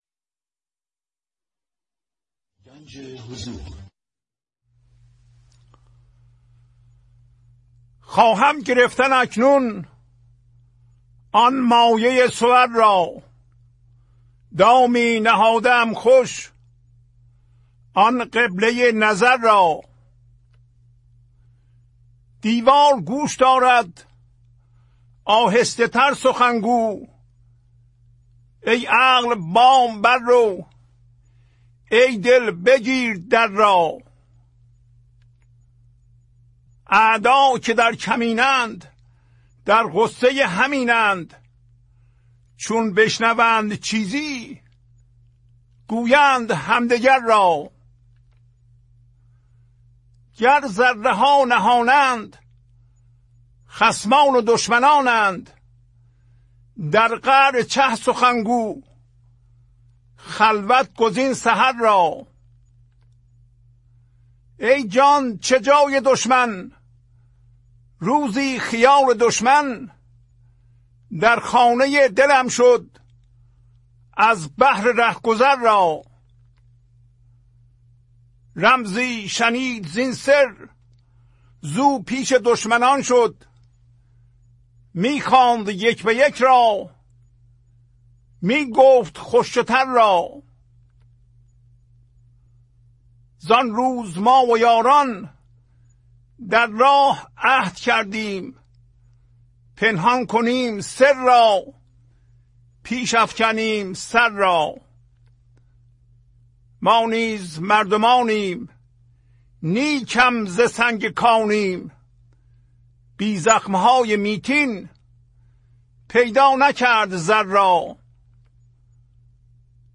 خوانش تمام ابیات این برنامه - فایل صوتی
1010-Poems-Voice.mp3